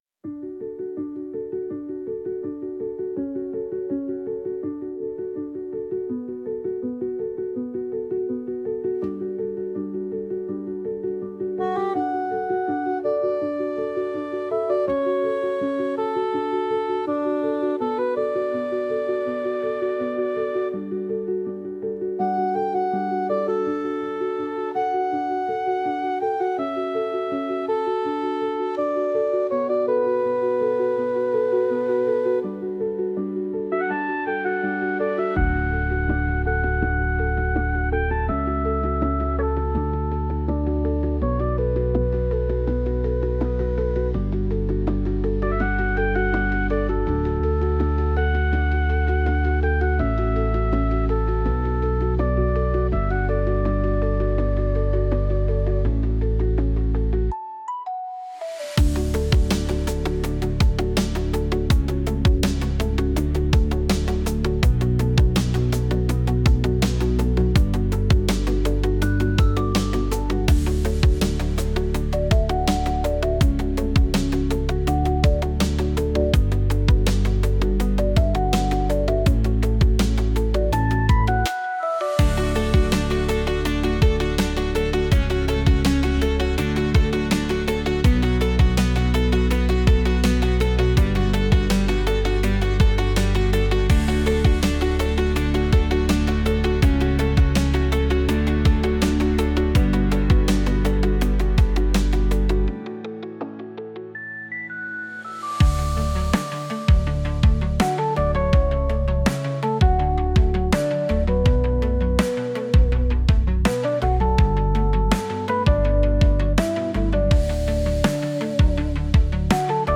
Instrumental -Lost in the Night 3.24